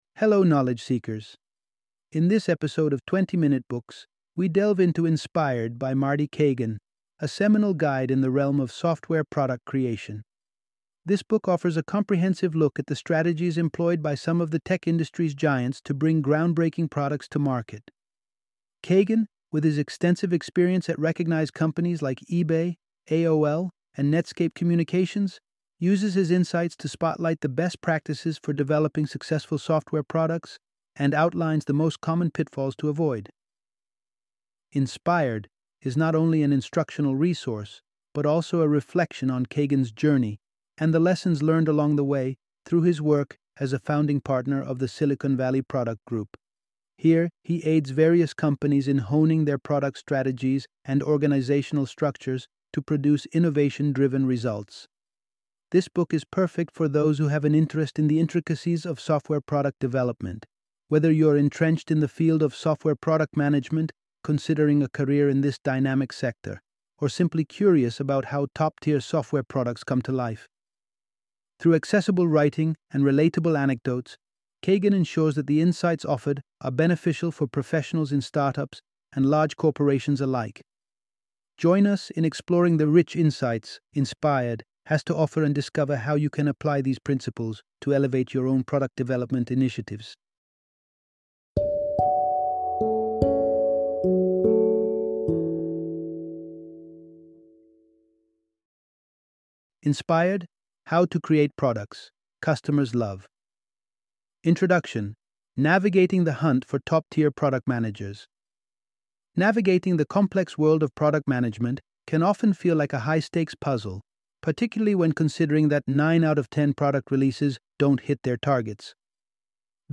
Inspired - Audiobook Summary